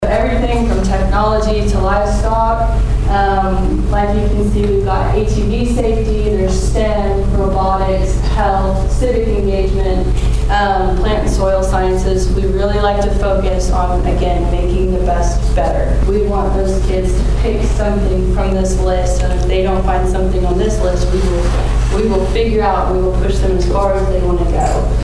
Wednesday's lunch and learn session took place at the community center in Pawhuska.